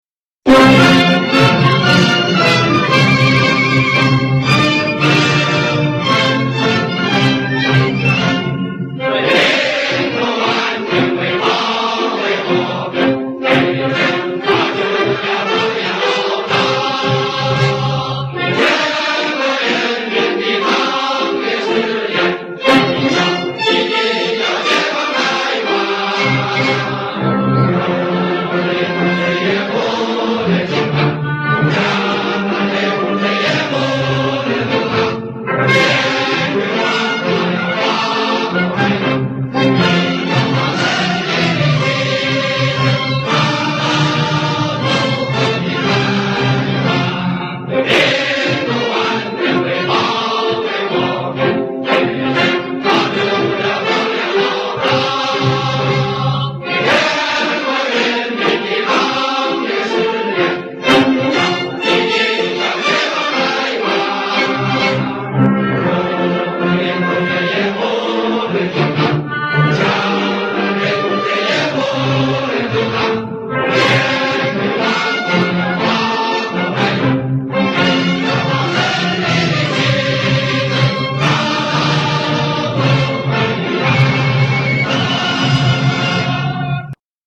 回复：求合唱歌曲：我们一定要解放台湾